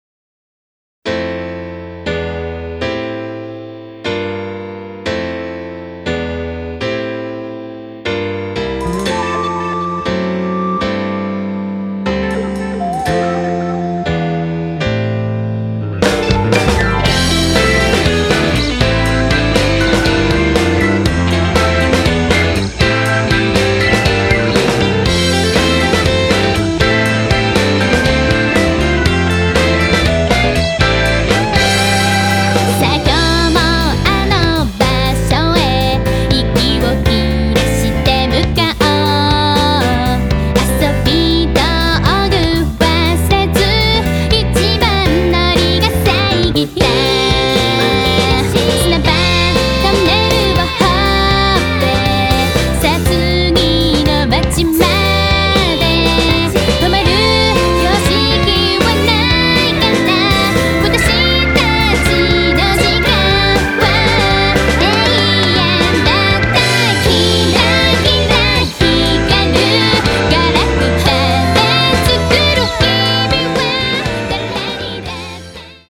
クロスフェードデモ
ポップ＆ロックの東方フルボーカルアルバムがここに完成！